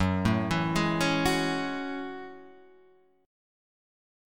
F#m9 chord {2 0 2 1 2 2} chord